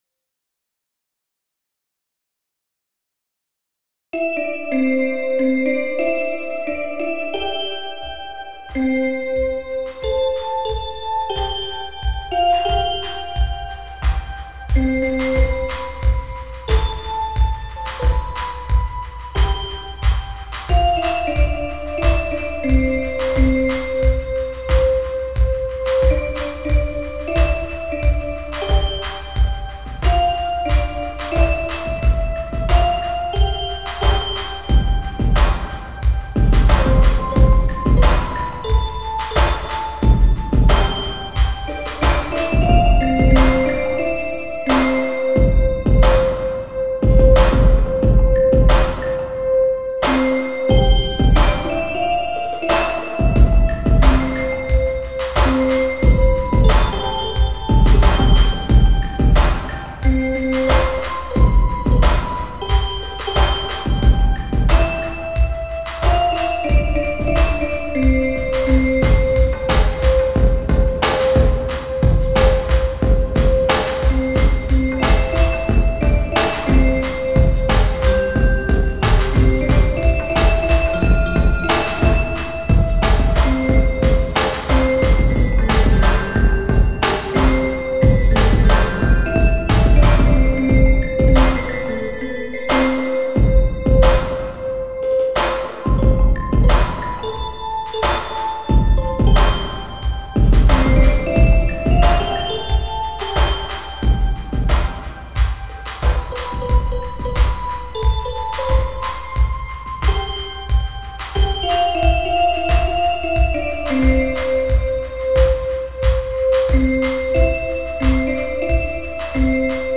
Posted in Dubstep, Other Comments Off on